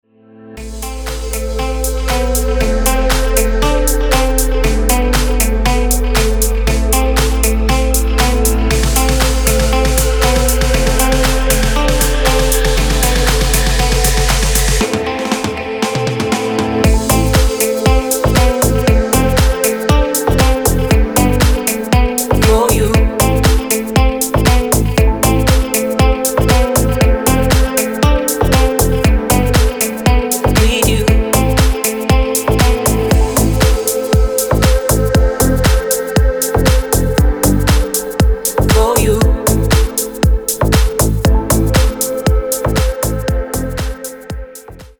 • Качество: 320, Stereo
deep house
атмосферные
Electronic
красивая мелодия
релакс
Стиль: deep house , electronic.